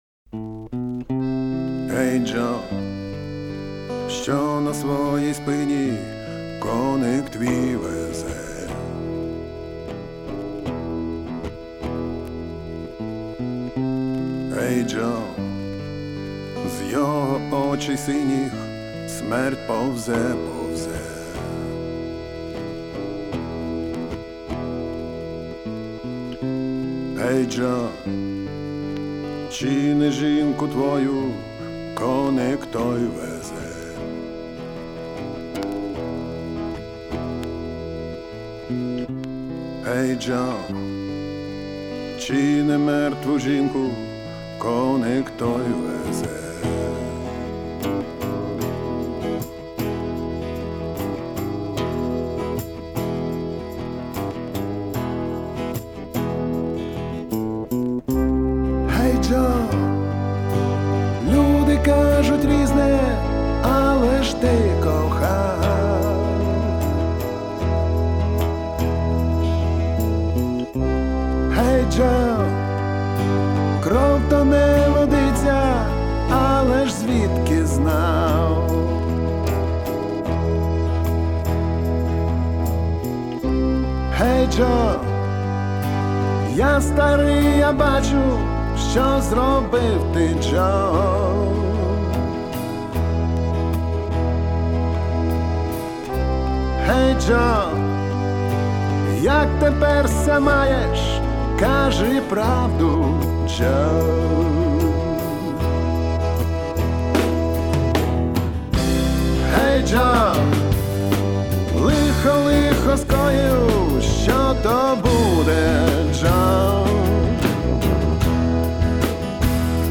Альтернативная музыка Фолк Рок